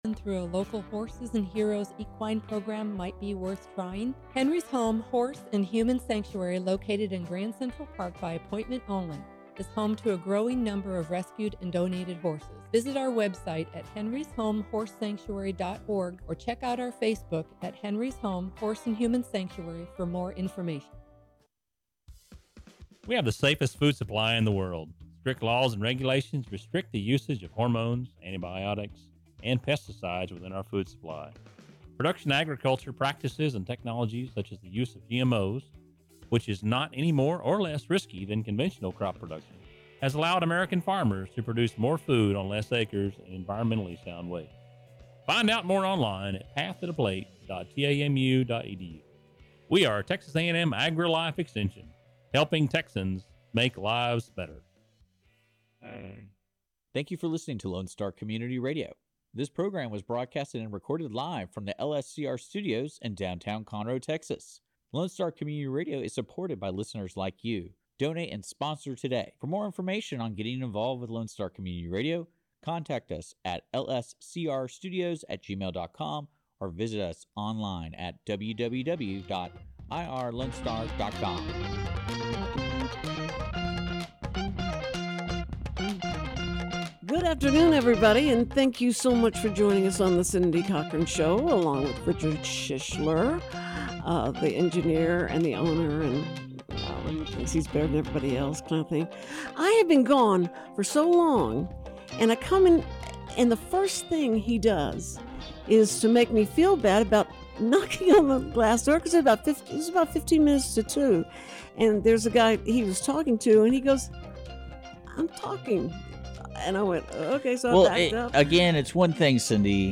morning live talk show